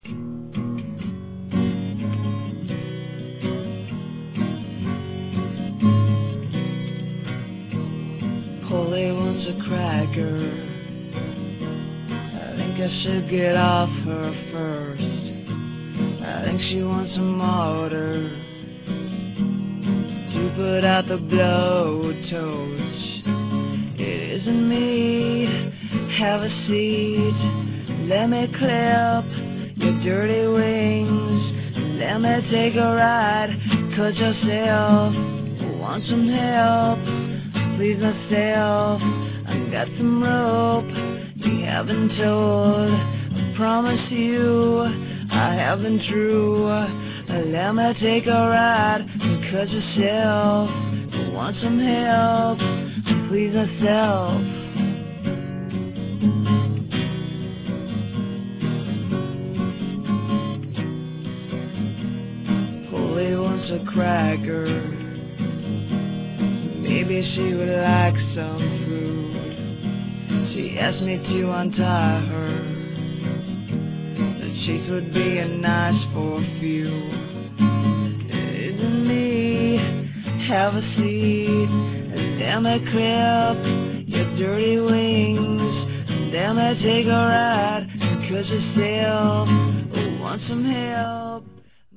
real audio - lead singer vocals (180k)
All of the existing versions were recorded in Chicago.